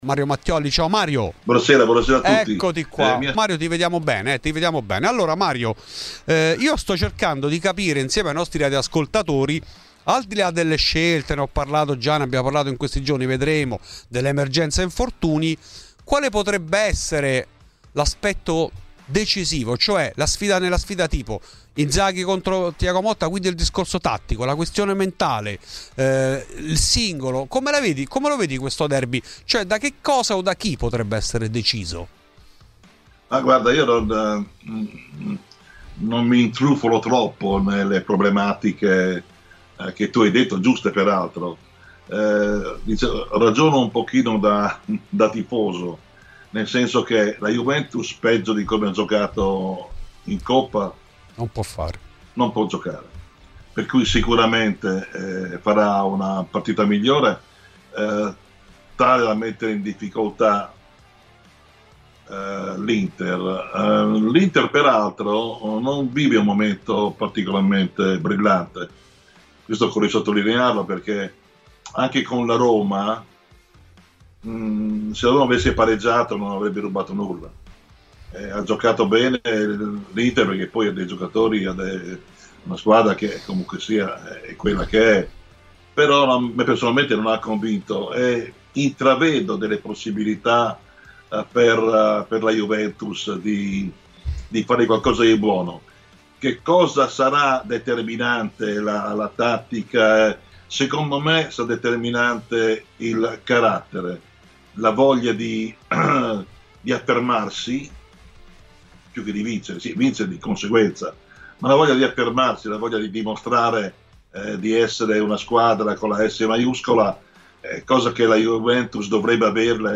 Nel corso della trasmissione '' Fuori di Juve '', su Radio Bianconera , è intervenuto il giornalista